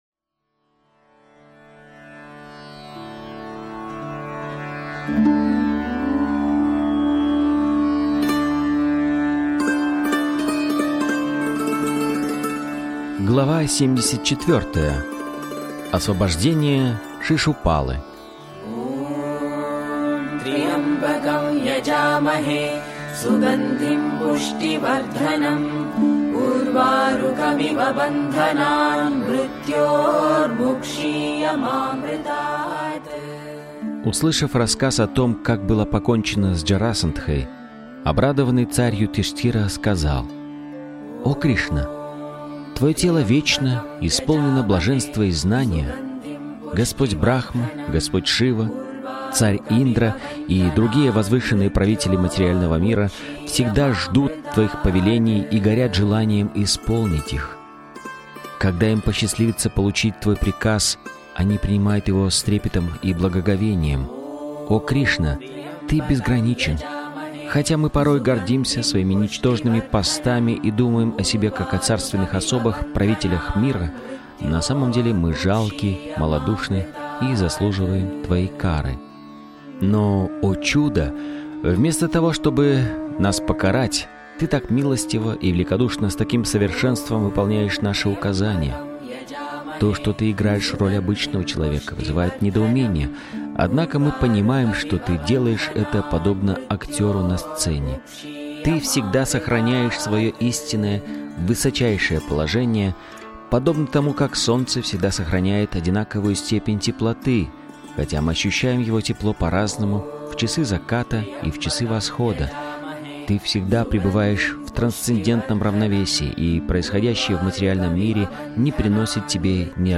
Srila Prabhupada 74 из книги Кришна. Источник вечного наслаждения автор Абхай Чаран Де Бхактиведанта Свами Прабхупада Информация о треке Автор аудиокниги : Абхай Чаран Де Бхактиведанта Свами Прабхупада Аудиокнига : Кришна.